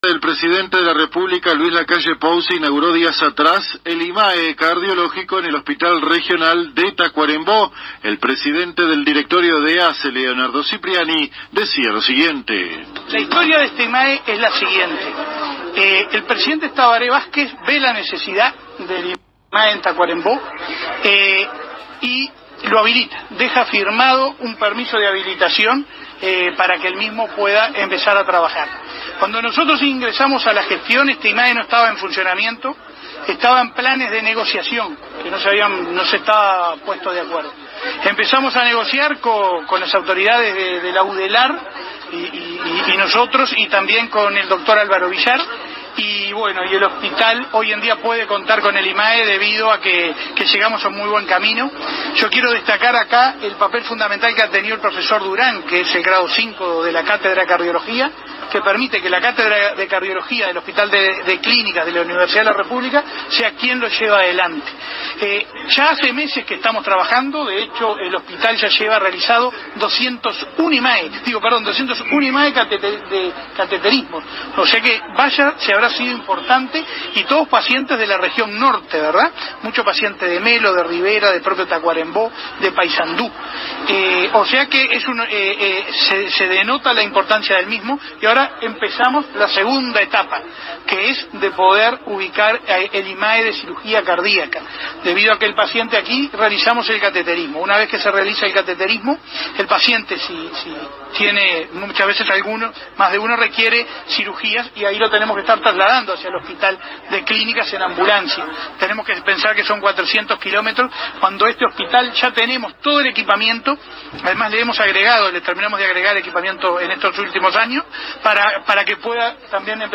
En el marco de la inauguración por parte del Presidente de la República Luis Lacalle Pou, del Instituto en Medicina Altamente Especializada (Imae) en Cardiología, días atrás en el Hospital de Tacuarembó de ASSE, el principal de este instituto, Leonardo Cipriani, conversó con la prensa para ofrecer detalles de tan importante centro de salud.
Escuche al Presdiente de ASSE, Leonardo Cipriani aquí: